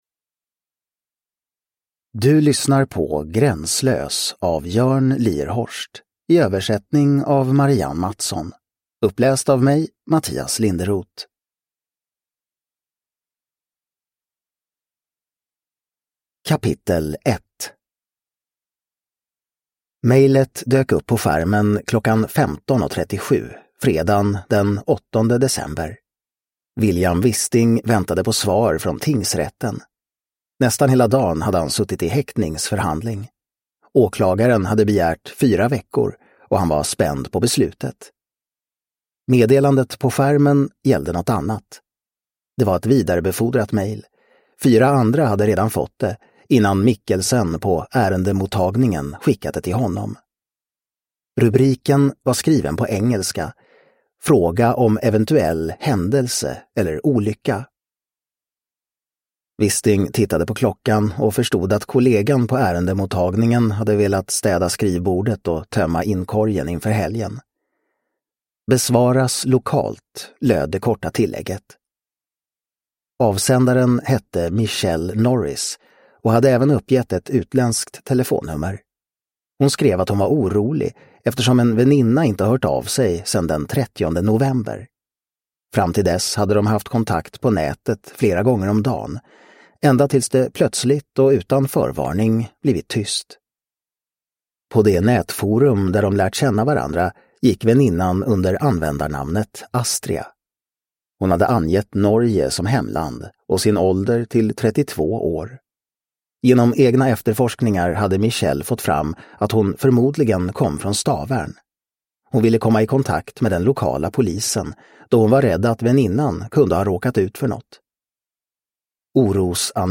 Gränslös – Ljudbok – Laddas ner